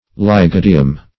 Lygodium \Ly*go"di*um\, n. [NL., fr. Gr.